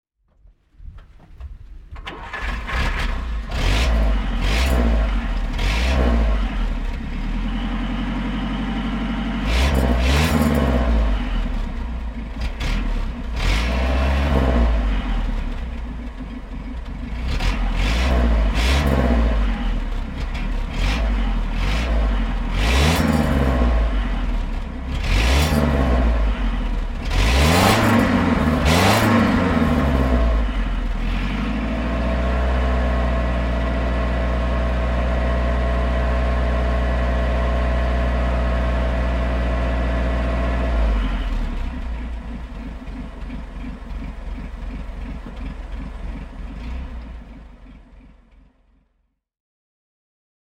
Mercedes-Benz 170 Va (1950) - Starten und Leerlauf